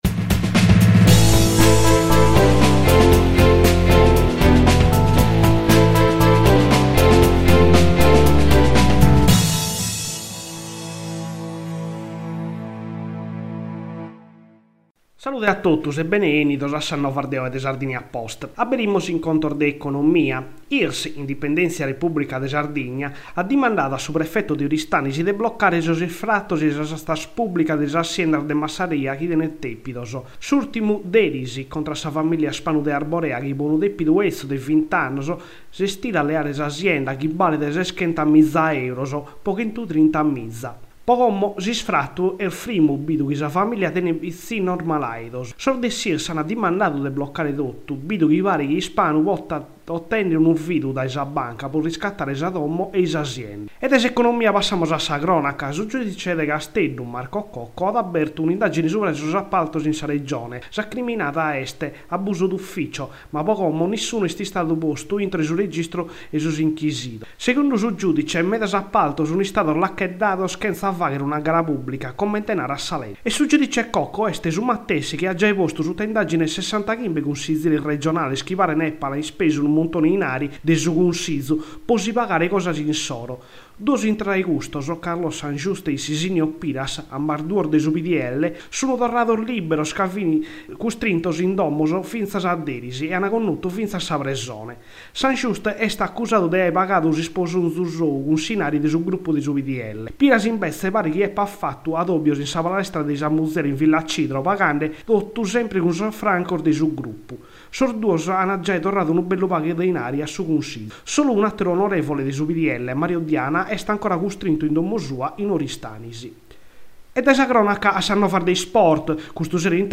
gr novas 5 marzo con sigla
gr-novas-5-marzo-con-sigla.mp3